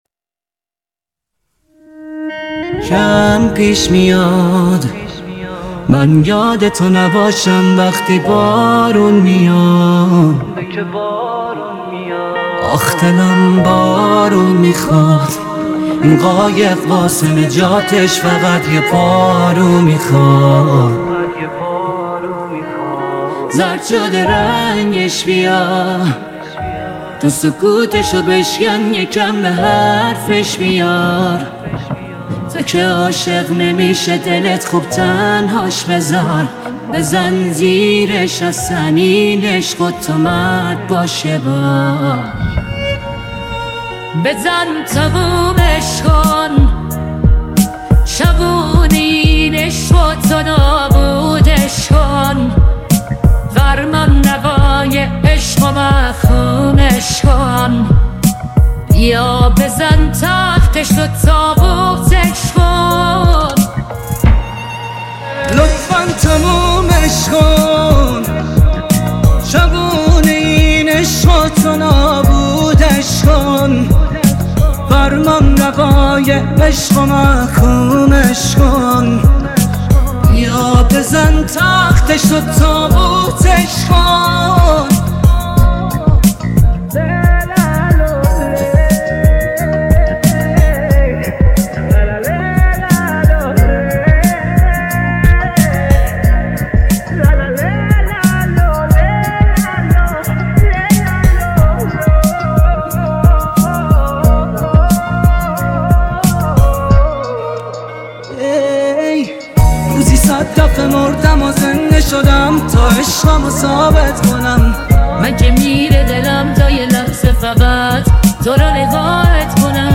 نوستالژی